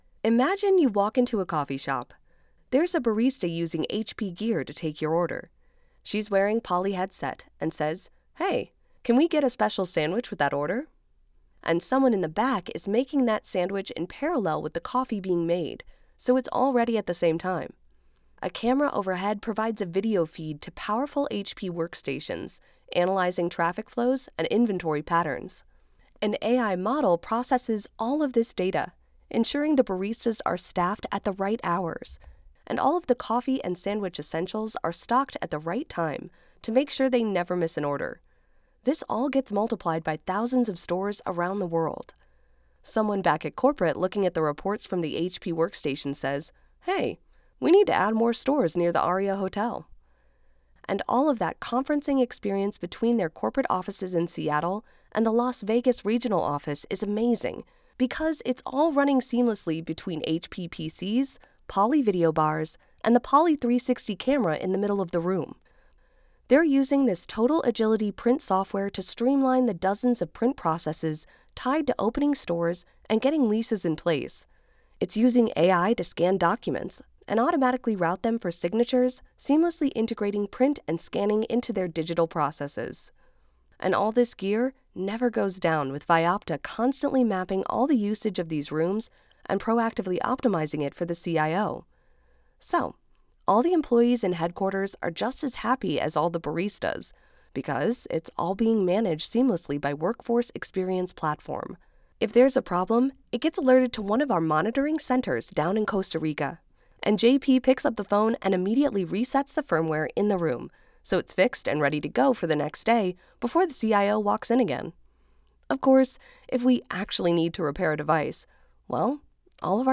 Female
Yng Adult (18-29), Adult (30-50)
E-Learning
Natural/Conversational Tone
Words that describe my voice are Warm, Friendly, Conversational.